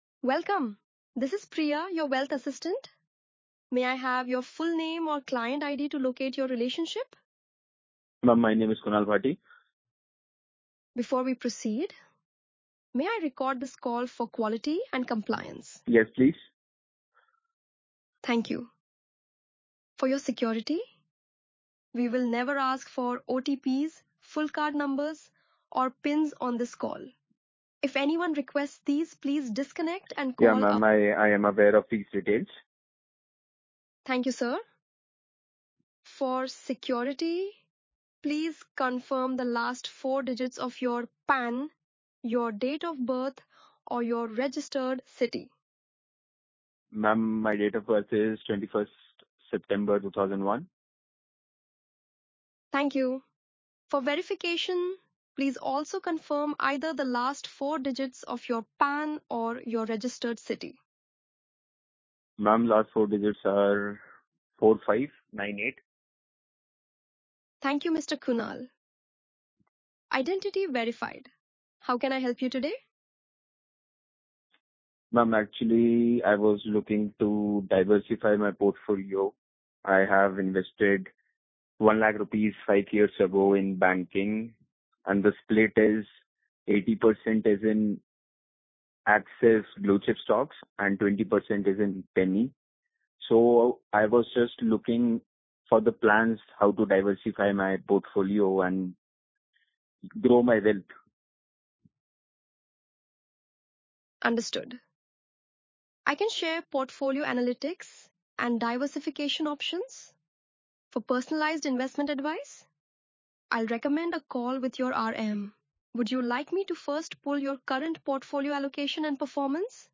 • English Indian
• Female